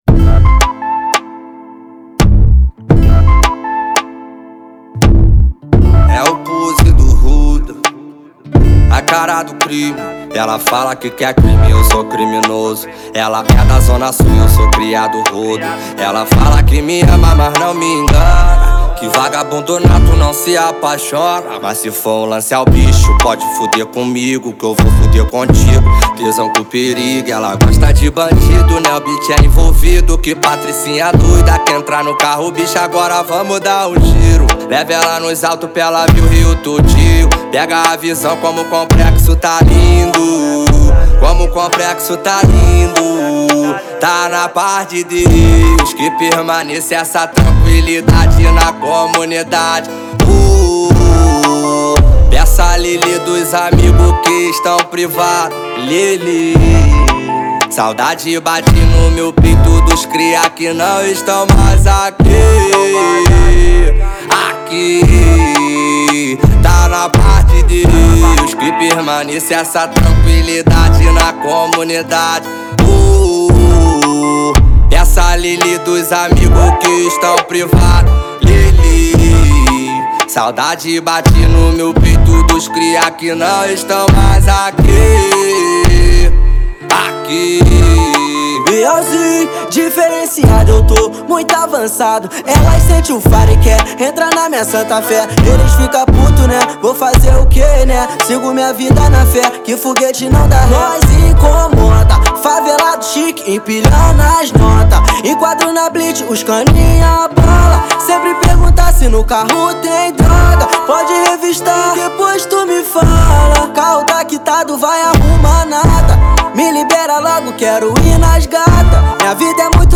2025-02-23 15:45:49 Gênero: Rap Views